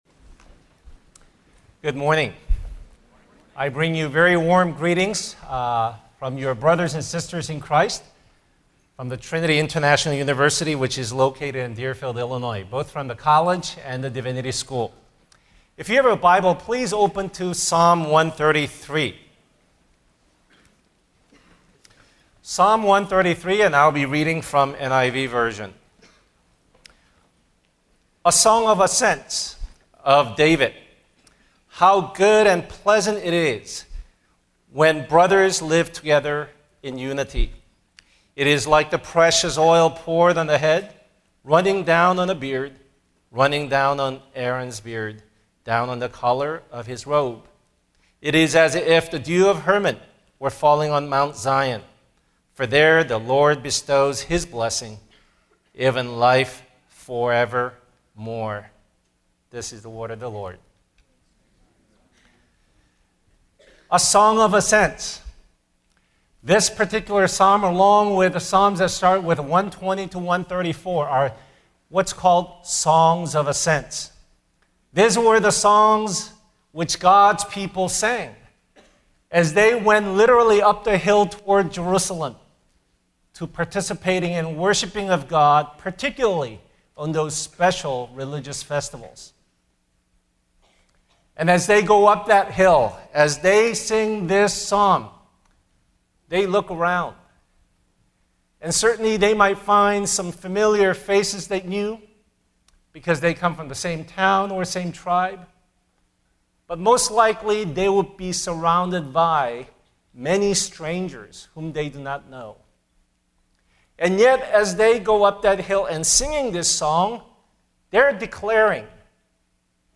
Culture Fest Chapel